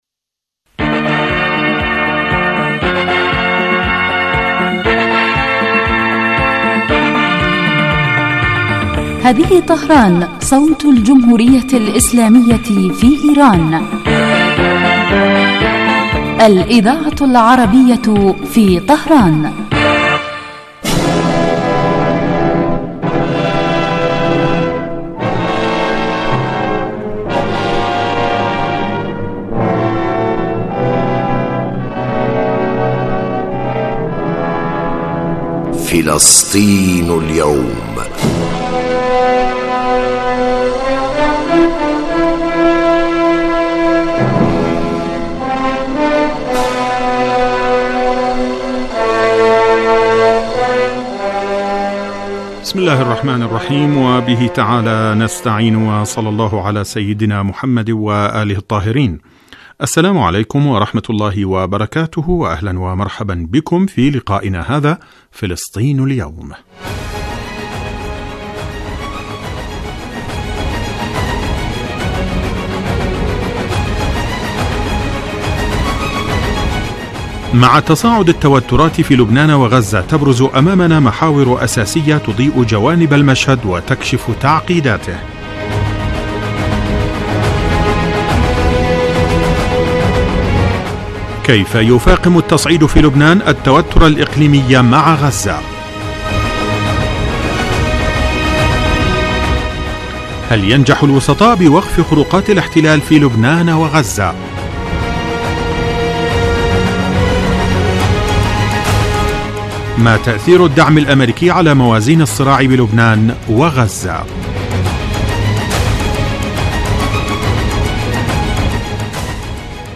برنامج يتناول تطورات الساحة الفلسطينية على كافة الصعد من خلال تقارير المراسلين واستضافة الخبراء في الشأن الفلسطيني.